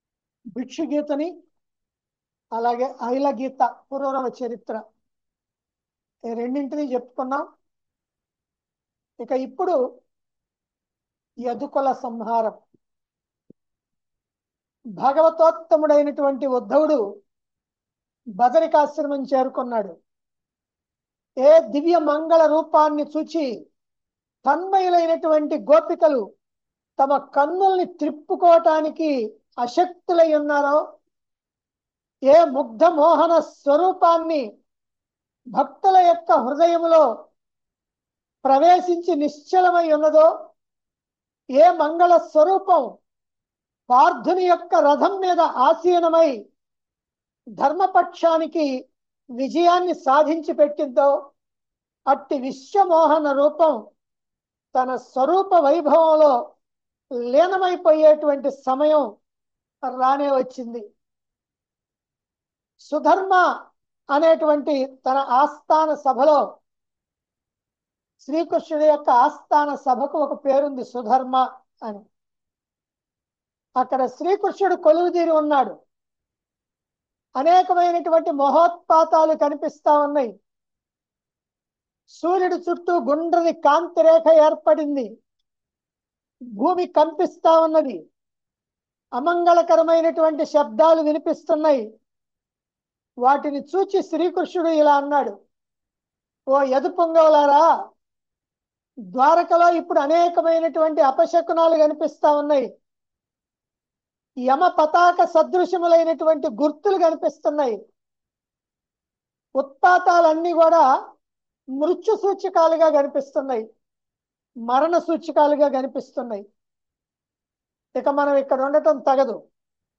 ప్రవచించిన ప్రవచనములు.